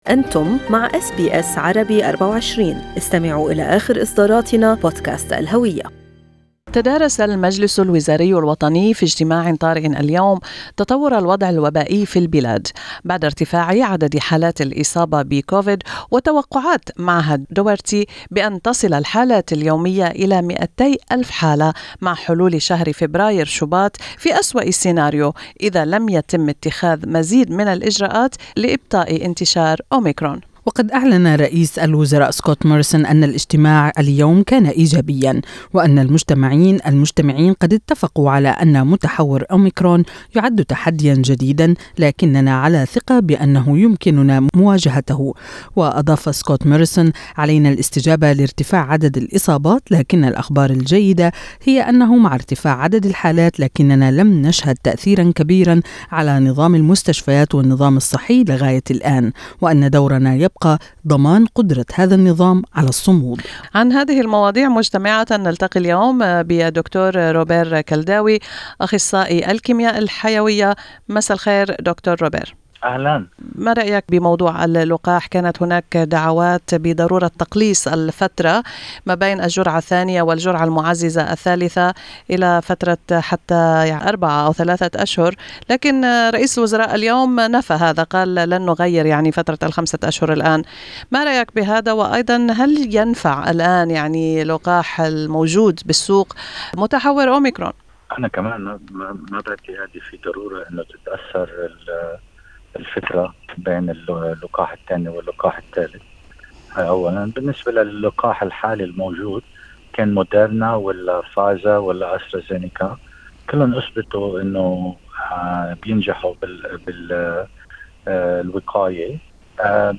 رئيس الوزراء الأسترالي سكوت موريسون يتحدث اليوم في مؤتمر صحفي بعد اجتماع طارئ للمجلس الوزراي الوطني Source: AAP